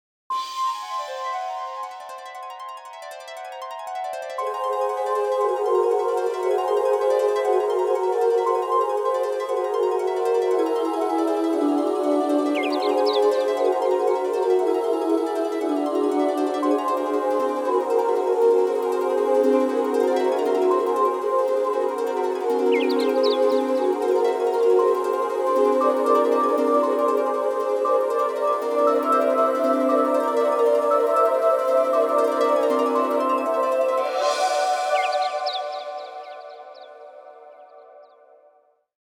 Synth sound